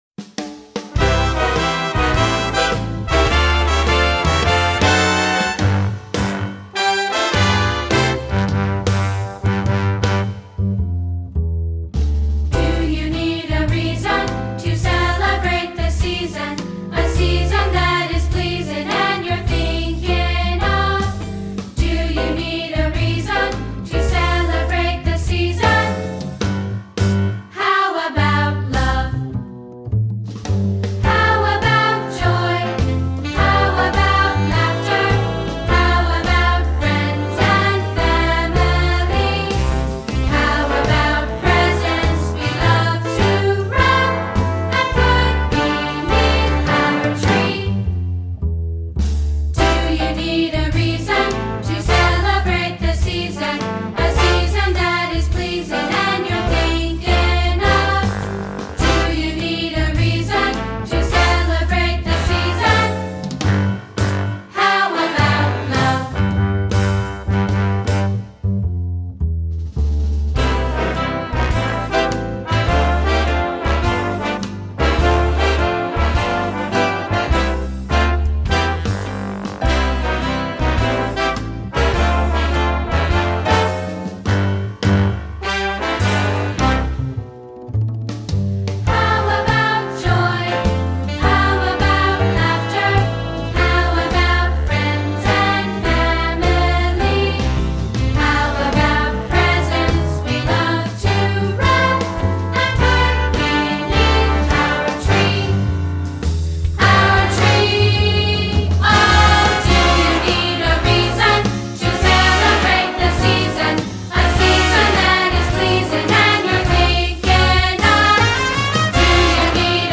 Genre: Children’s Music.